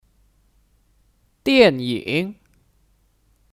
电影 (Diànyǐng 电影)